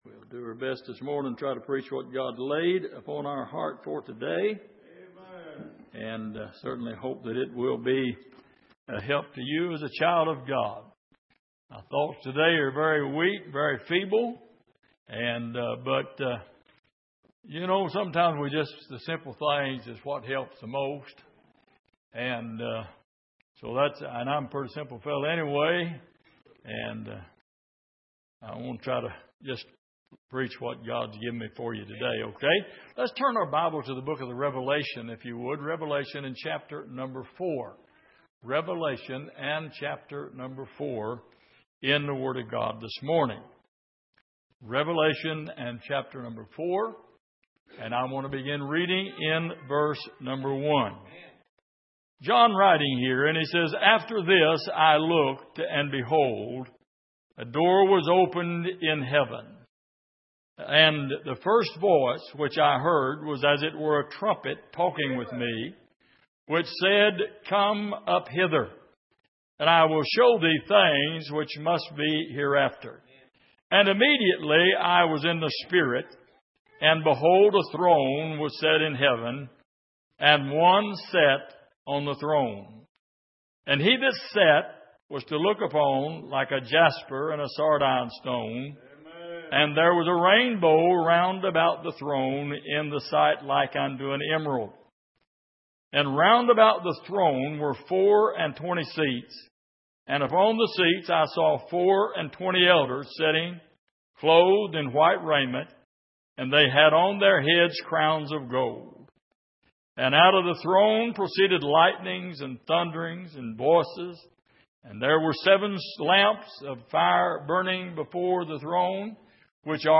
Miscellaneous Passage: Revelation 4:1-11 Service: Sunday Morning When We All Get To Heaven « What Is A Chistian?